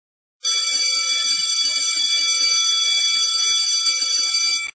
0 Timbre entrada
Tags: martillo